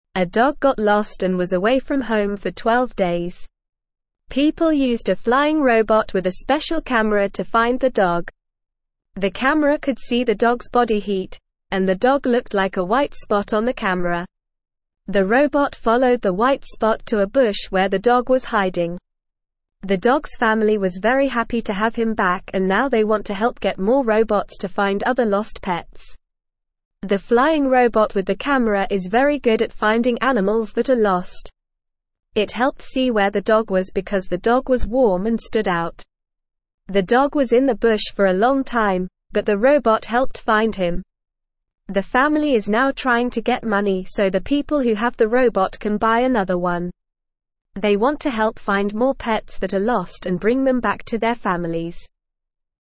English-Newsroom-Beginner-NORMAL-Reading-Flying-Robot-Helps-Find-Lost-Dog-Family-Wants-More.mp3